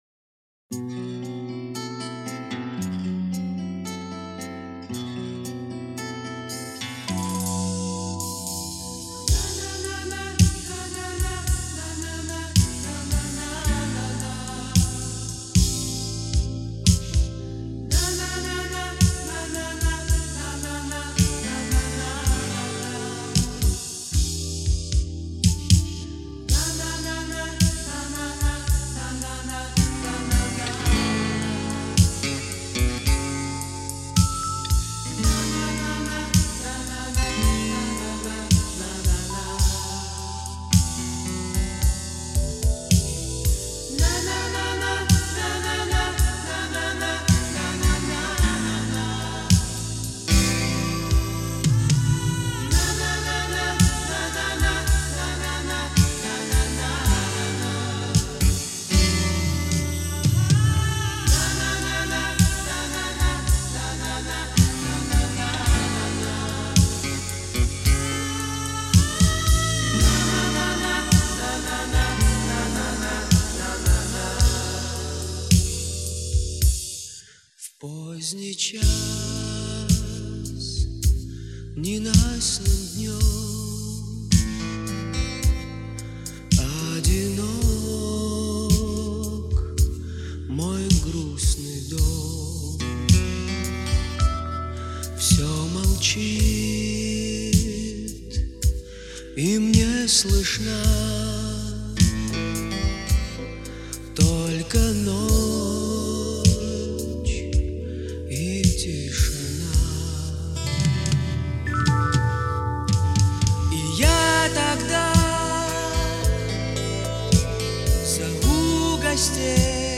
гитара, вокал
клавишные
бас, вокал
ударные
рок-музыка, советская эстрада